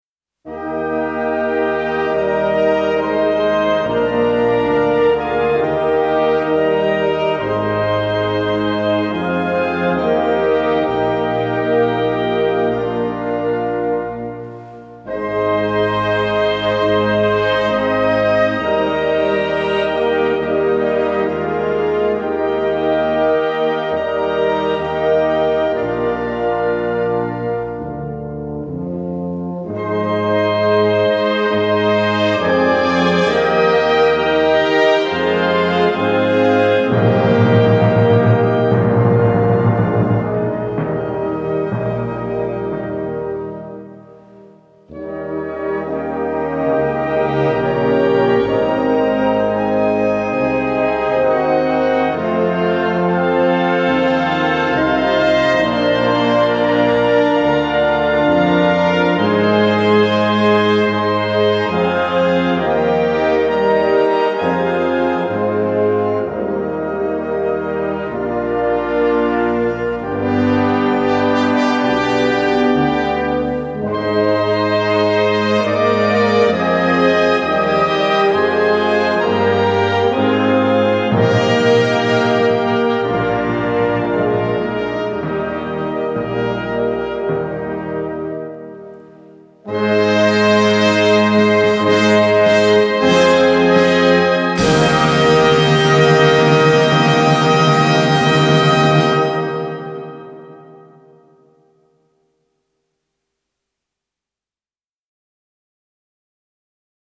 Besetzung: Blasorchester